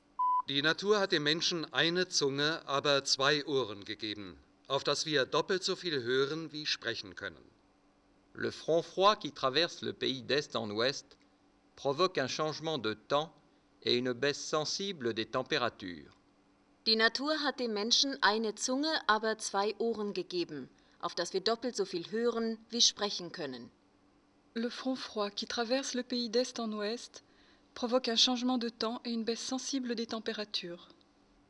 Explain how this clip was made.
ownVoice_shaker0.2g_speaker80dBSPL_PDM.wav